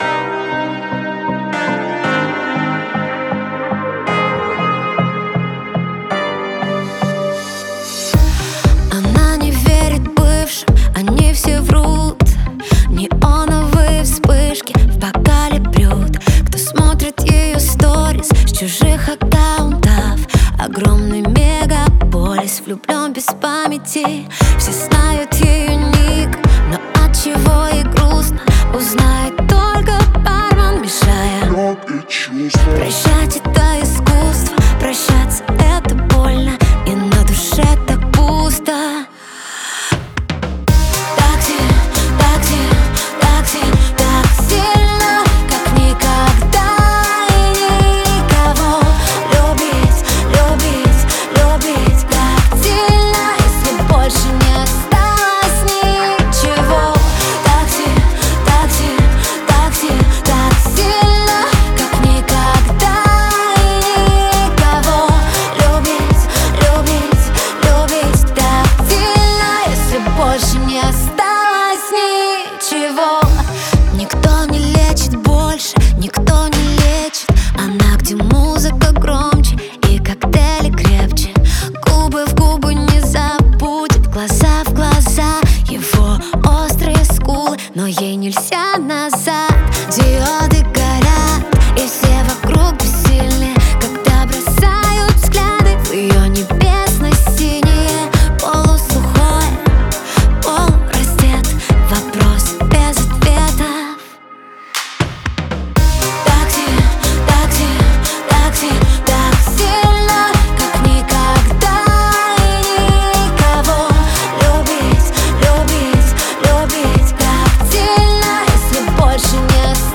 яркая поп-баллада
отличается мелодичностью и сильным вокалом исполнительницы